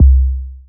rep - 808(toomp).wav